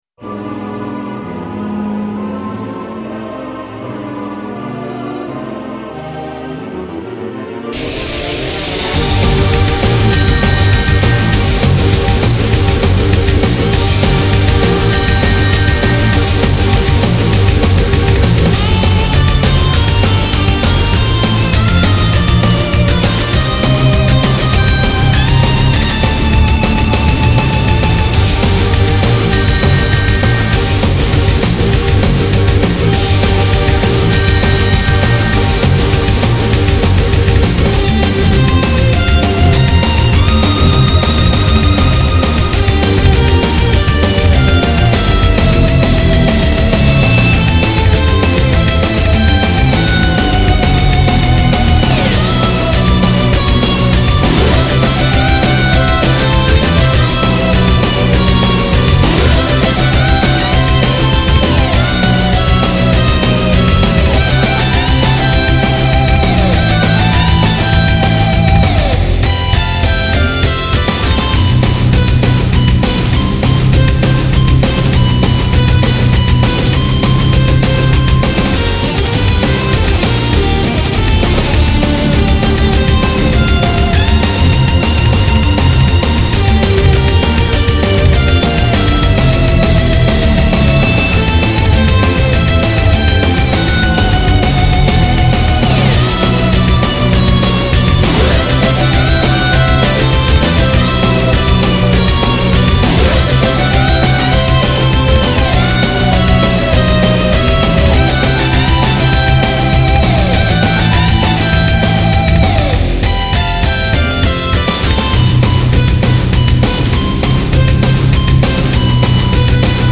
音源モジュール YAMAHA MU2000